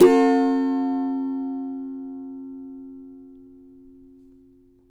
CAVA G MJ  D.wav